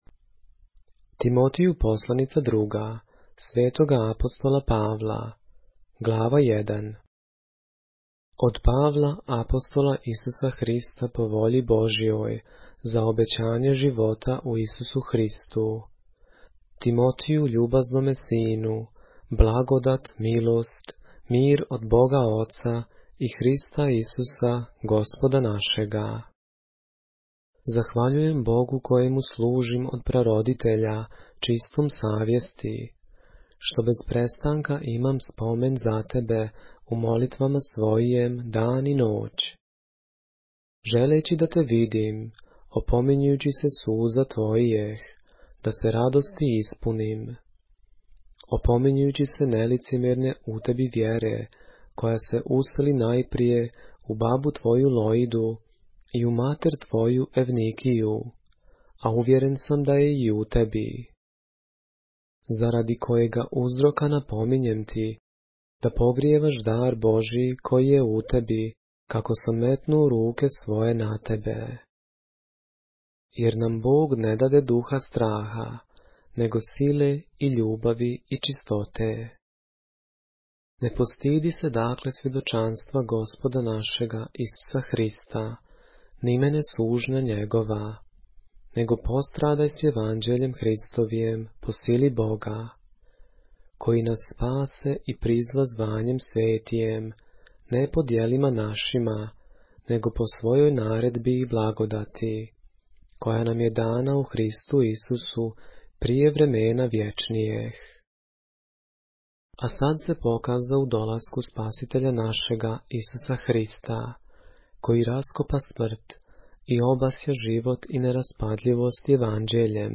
поглавље српске Библије - са аудио нарације - 2 Timothy, chapter 1 of the Holy Bible in the Serbian language